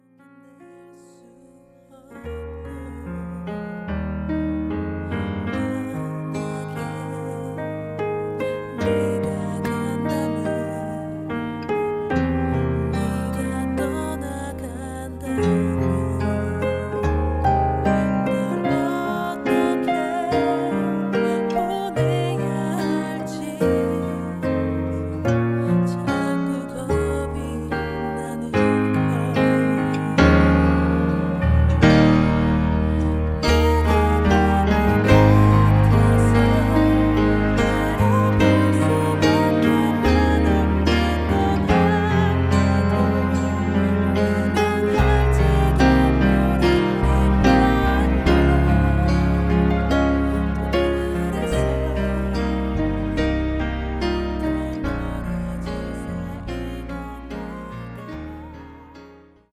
음정 -1키 4:26
장르 가요 구분 Voice Cut